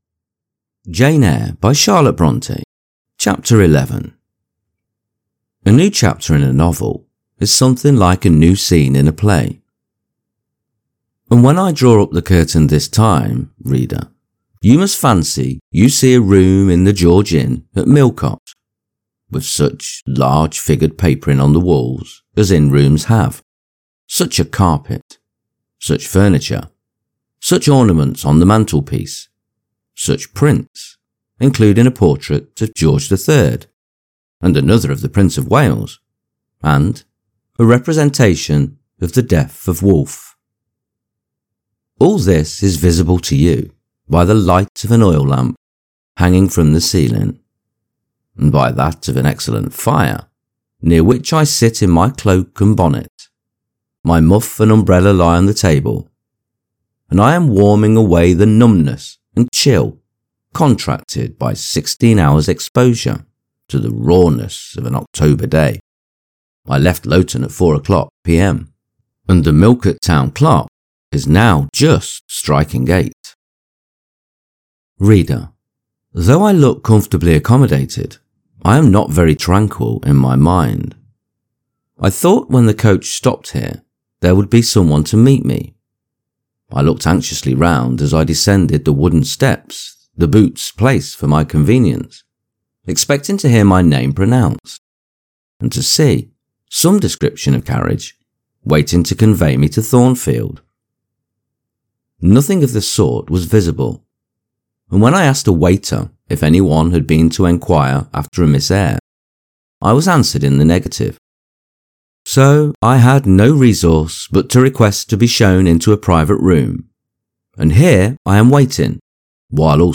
Jane Eyre – Charlotte Bronte – Chapter 11 | Narrated in English - Dynamic Daydreaming